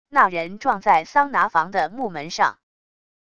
那人撞在桑拿房的木门上wav音频